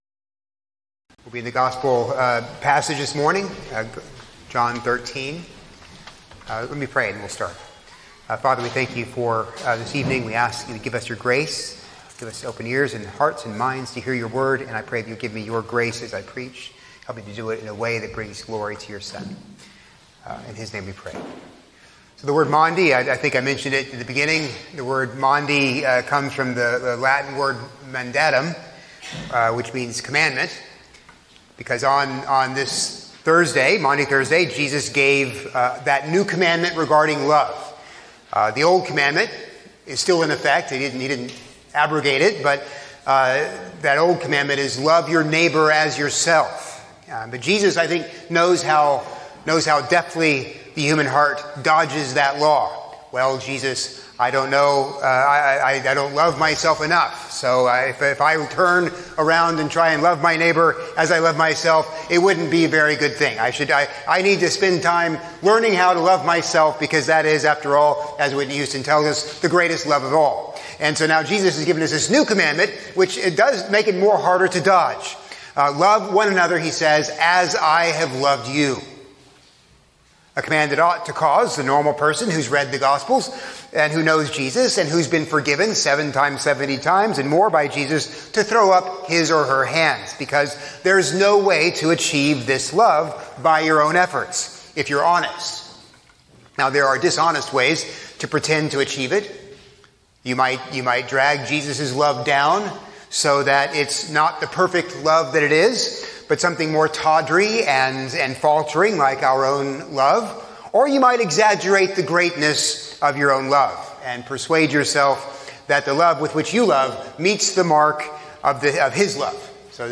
from April 2, 2026 (Maundy Thursday)